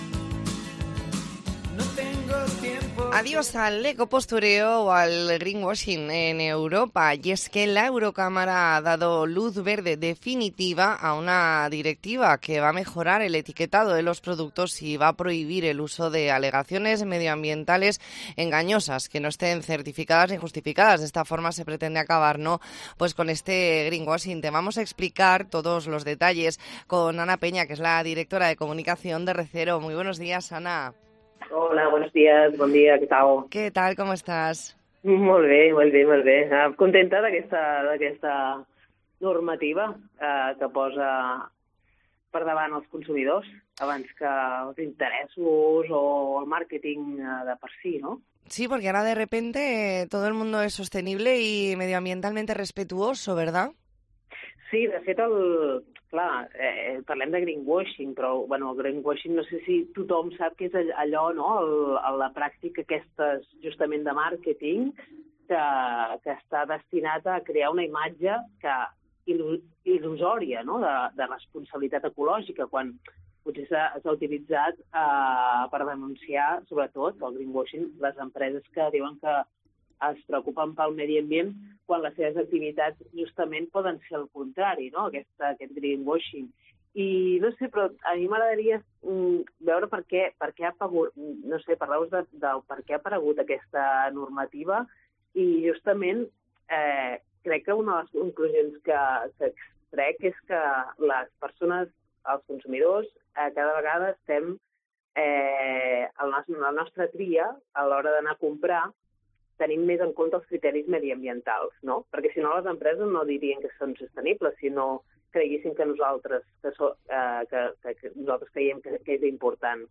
Entrevista en Mediodía COPE Baleares, 22 de enero de 2024.